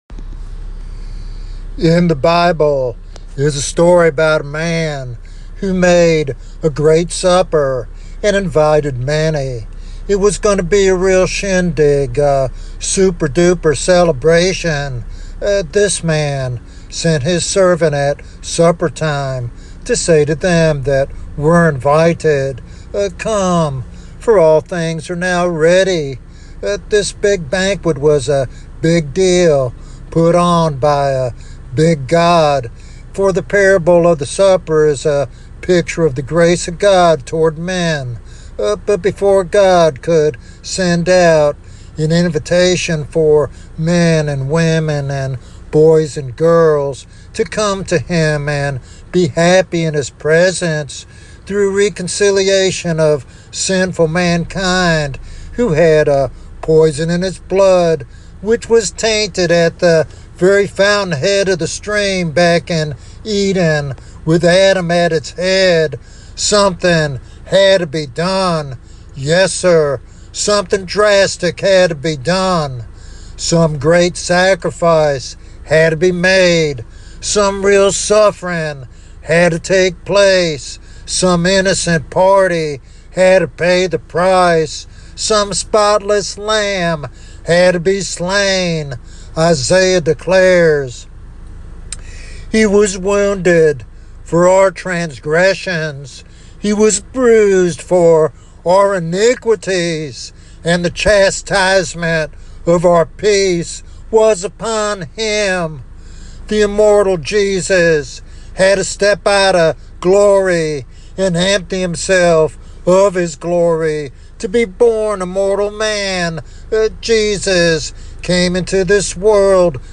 This sermon powerfully reminds believers and seekers alike of the hope and salvation found only in Jesus Christ.
Sermon Outline